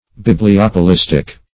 Search Result for " bibliopolistic" : The Collaborative International Dictionary of English v.0.48: Bibliopolistic \Bib`li*op`o*lis"tic\, a. Of or pertaining to bibliopolism.